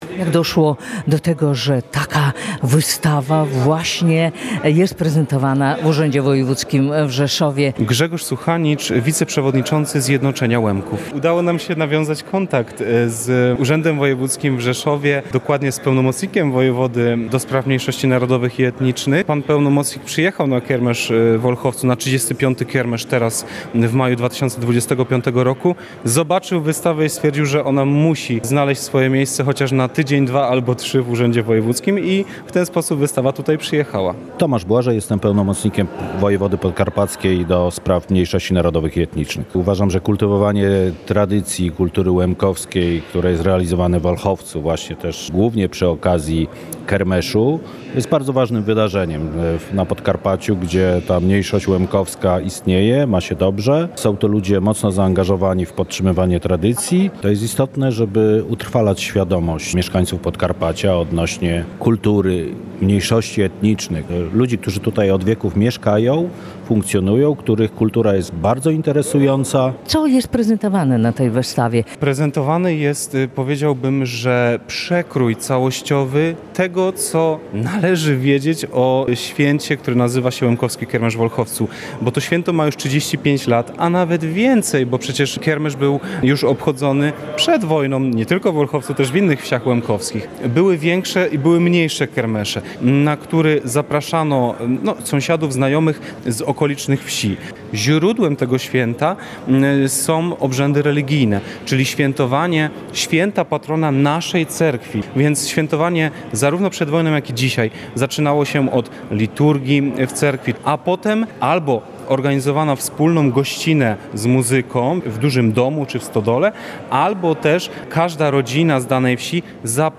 Łemkowie są bogactwem Podkarpacia – powiedziała na uroczystości otwarcia wystawy wojewoda podkarpacki Teresa Kubas – Hul.